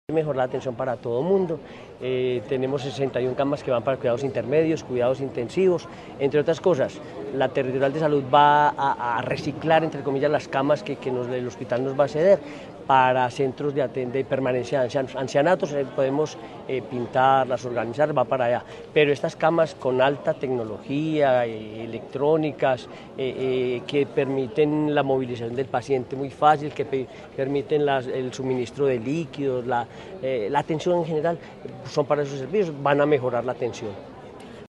Henry Gutiérrez Ángel, gobernador de Caldas.